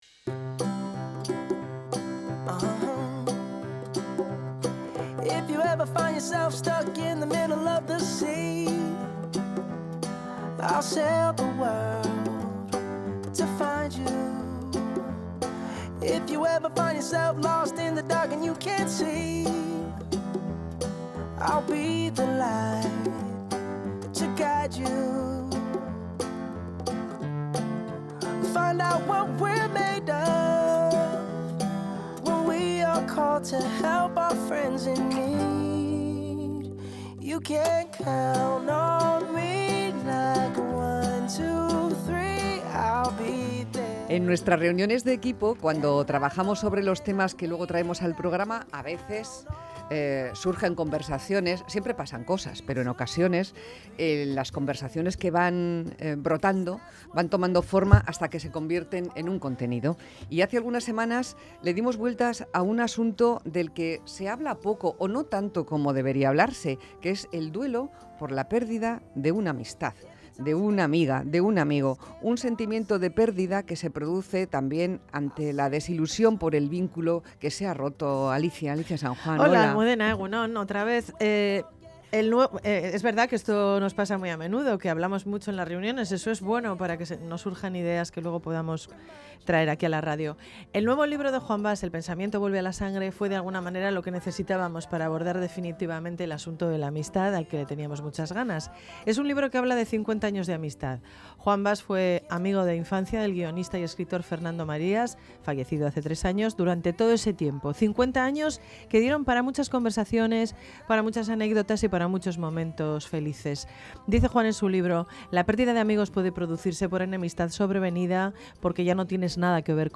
Entrevista con Juan Bas
Programa-Mas-que-palabras-en-Radio-Euskadi.mp3